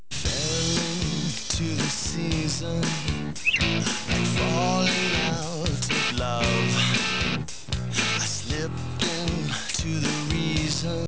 Sound bytes were captured from the vh-1 Special of Leif Garrett:  Behind The  Music and Where Are They Now.